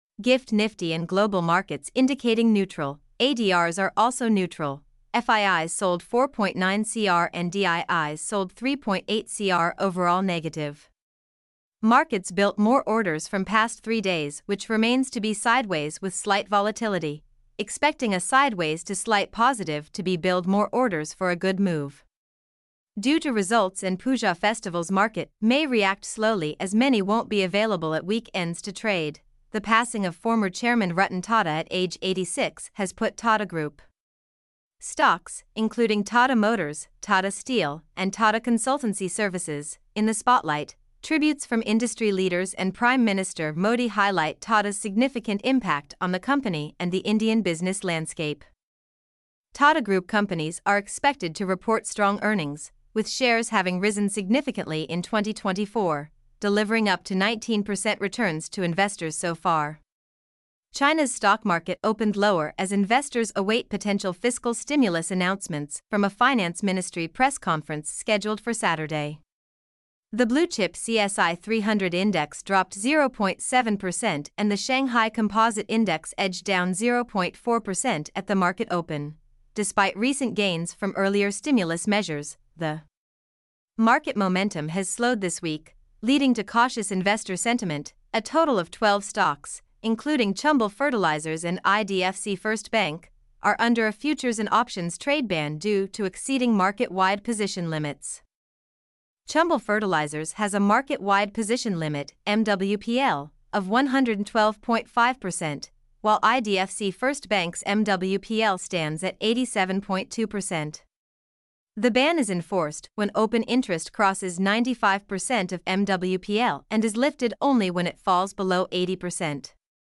mp3-output-ttsfreedotcom-1-1.mp3